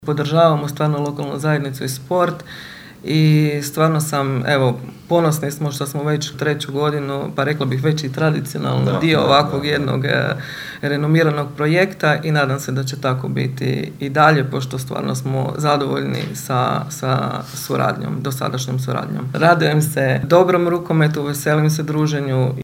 na konferenciji za novinare